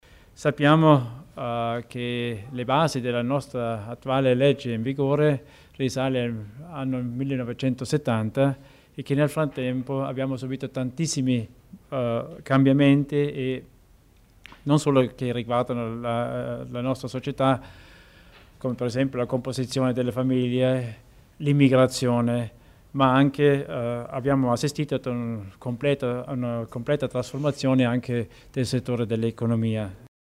L'Assessore Richard Theiner spiega le novità in tema di urbanistica
La nuova legge provinciale su sviluppo del territorio e tutela del paesaggio dovrà essere chiara e comprensibile, definire limiti precisi all’urbanizzazione delle poche superfici ancora disponibili, valorizzare sul piano tecnico gli organi di consulenza. Lo ha ribadito oggi (28 aprile) l’assessore provinciale Richard Theiner dopo la seduta della Giunta provinciale illustrandone le linee di fondo.